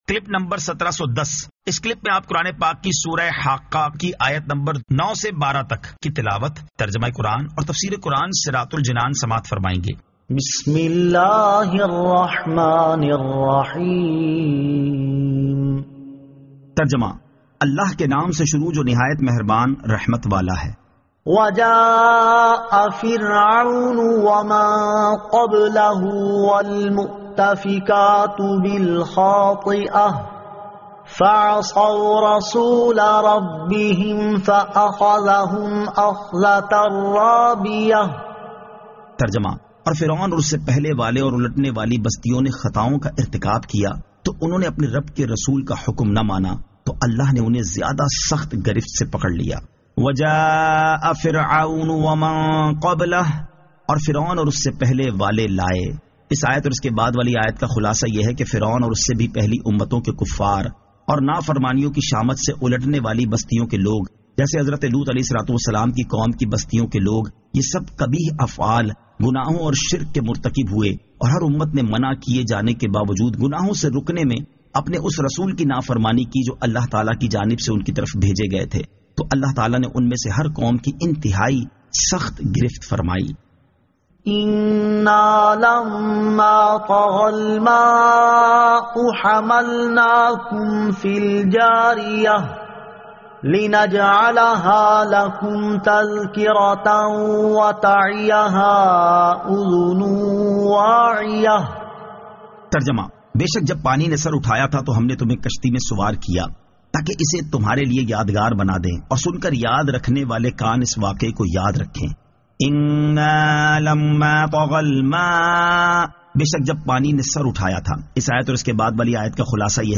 Surah Al-Haqqah 09 To 12 Tilawat , Tarjama , Tafseer